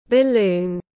Bóng bay tiếng anh gọi là balloon, phiên âm tiếng anh đọc là /bəˈluːn/
Balloon.mp3